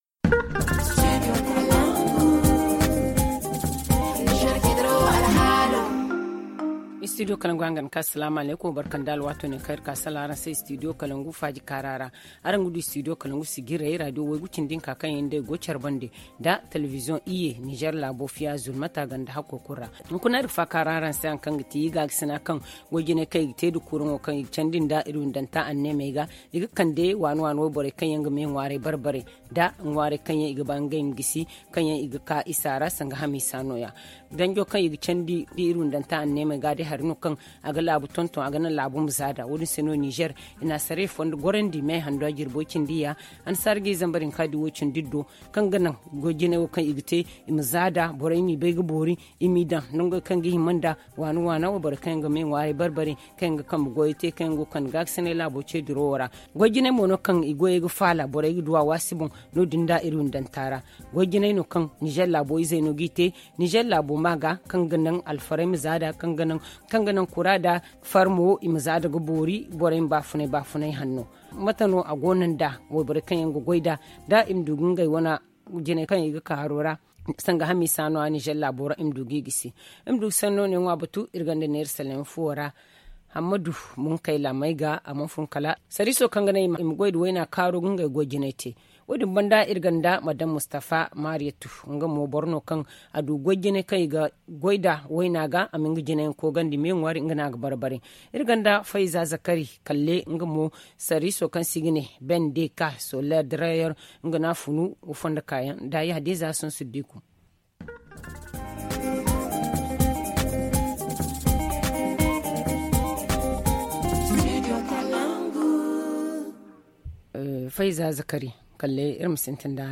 reçoit dans ce studio
Le forum en zarma